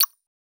Epic Holographic User Interface Click 2.wav